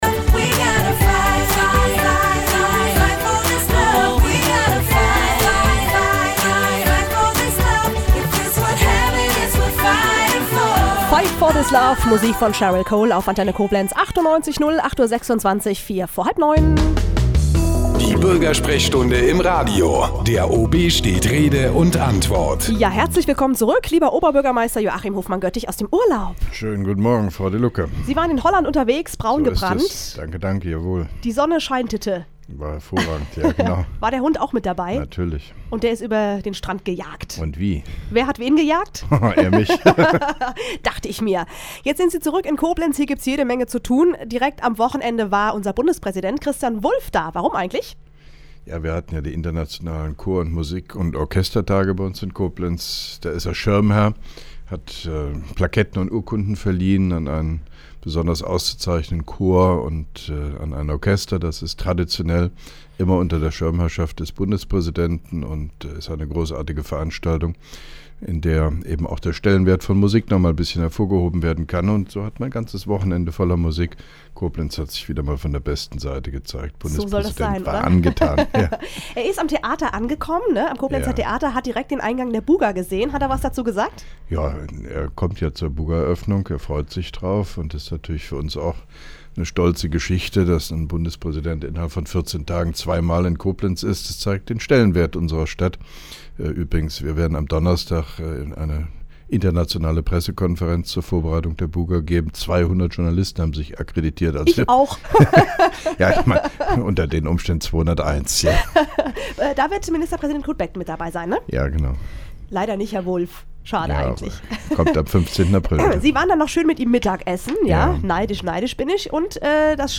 (1) Koblenzer Radio-Bürgersprechstunde mit OB Hofmann-Göttig 05.04.2011